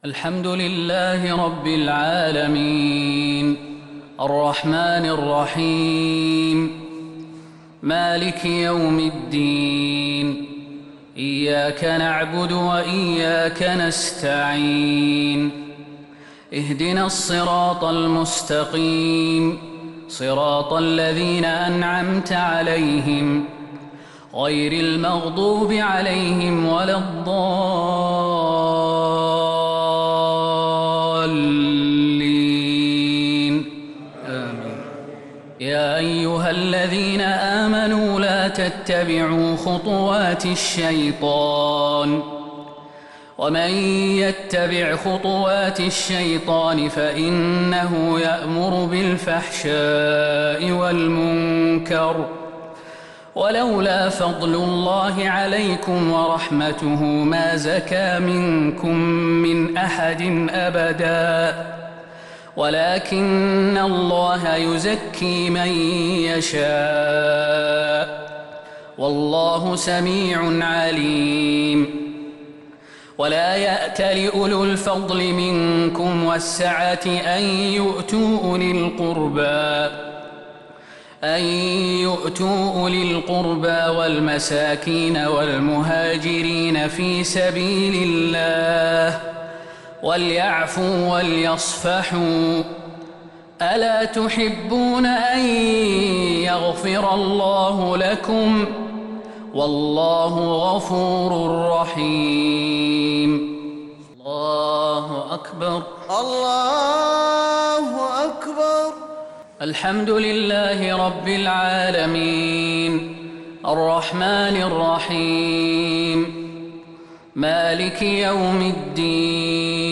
صلاة المغرب للقارئ خالد المهنا 17 شوال 1442 هـ